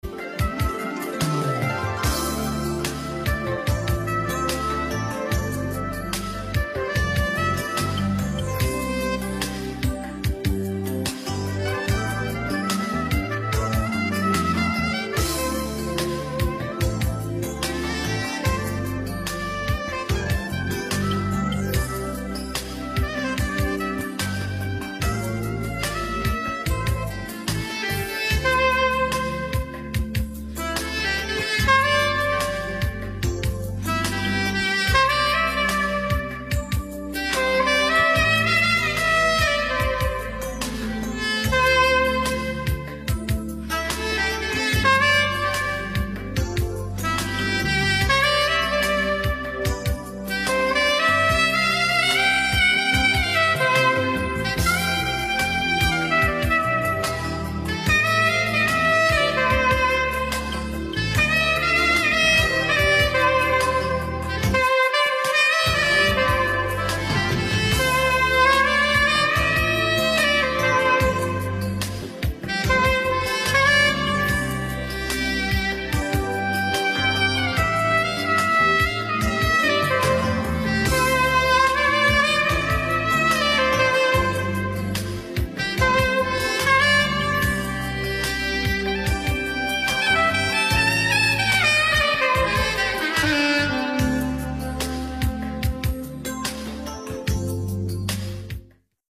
sax